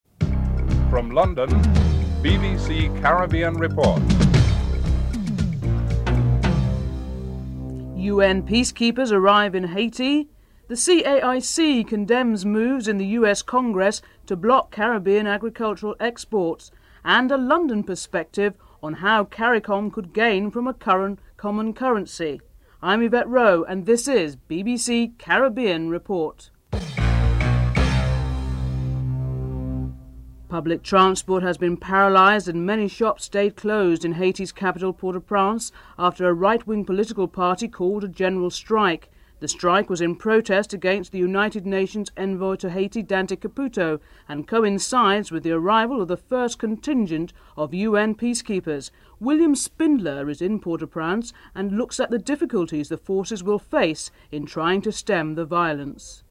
Dame Eugenia Charles, Prime Minister of Dominica in an interview responds positively to the question of Dominica’s opportunity to give tangible support to Haiti by sending troops (00:30 -05:03).